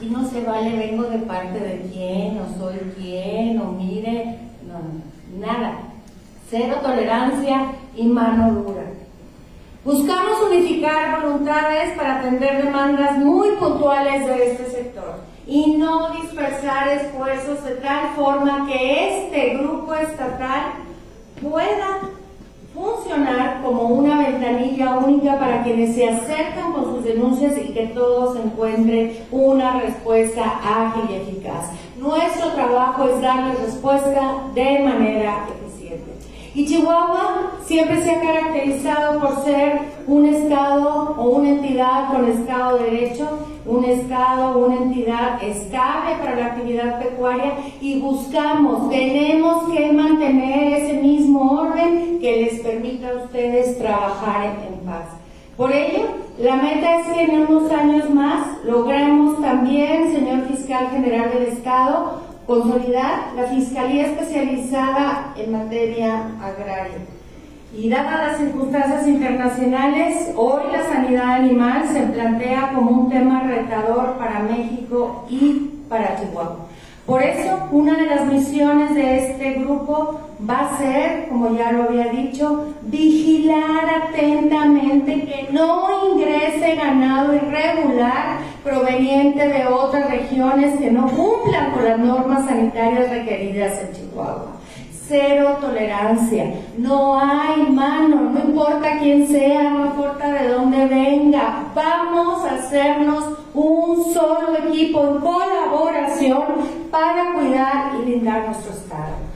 La presentación tuvo lugar en un evento celebrado en la Unión Ganadera Regional de Chihuahua .